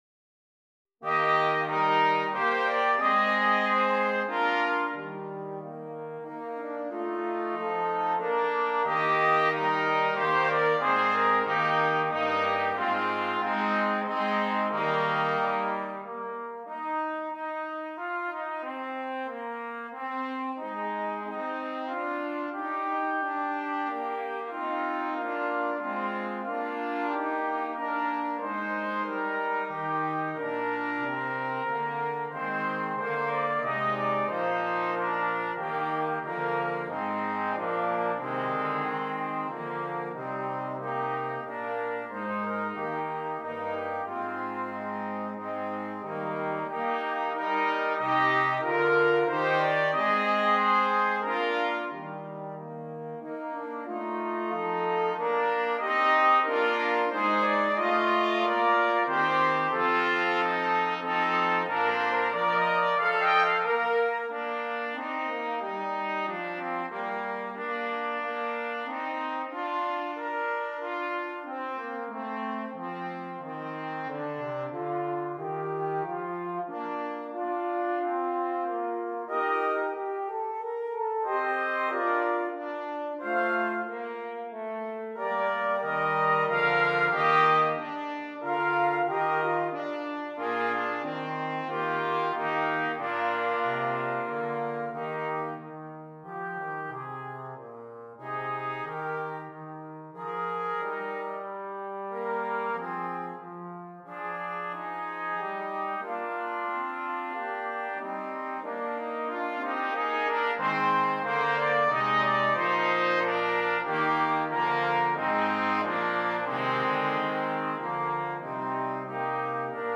Brass Quartet
Irish Air